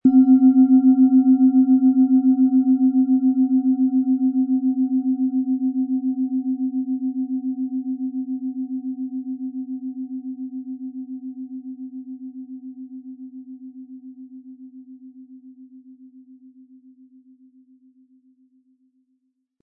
• Mittlerer Ton: Wasser
PlanetentöneSonne & Wasser
MaterialBronze